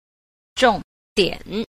5. 重點 – zhòngdiǎn – trọng điểm